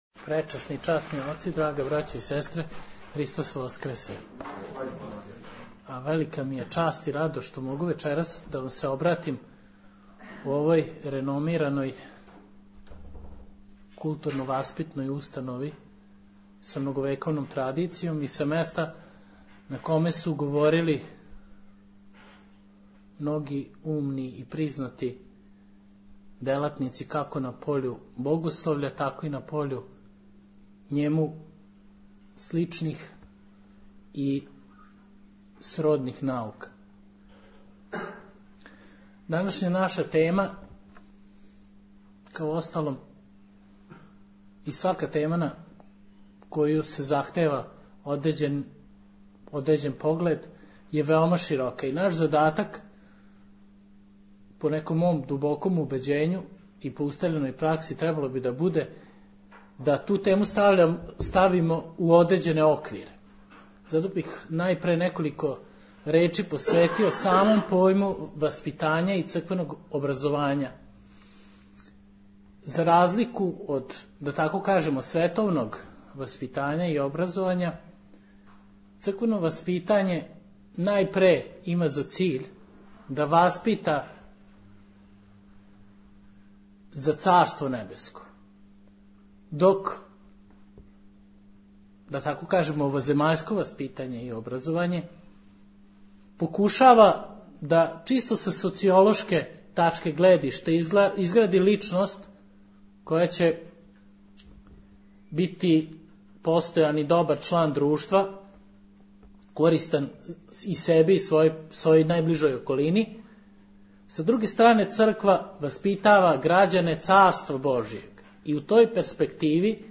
Звучни запис предавања